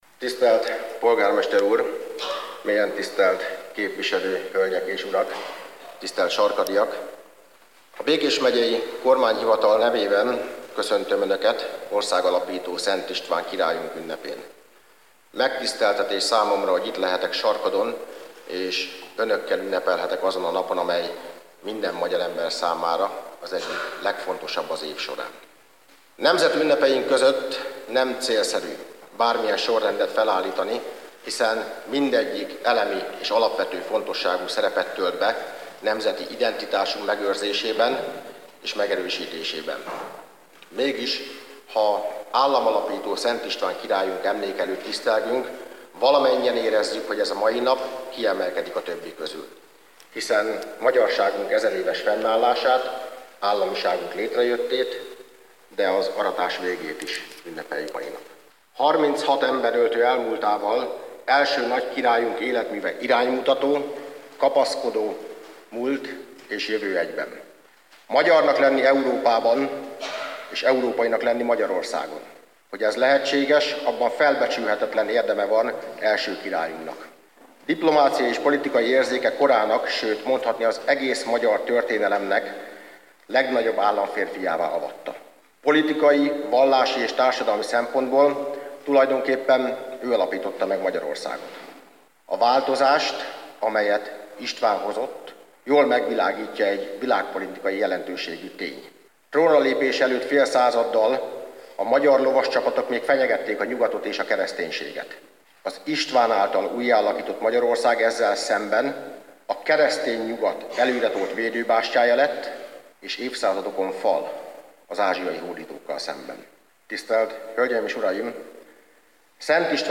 Az Államalapító Szent István királyunk tiszteletére rendezett városi ünnepség a sarkadi Római Katolikus Temlomban került megrendezésre. Ünnepi beszédet mondott dr. Takács Árpád, a Békés Megyei Kormányhivatalt vezető kormánymegbízott.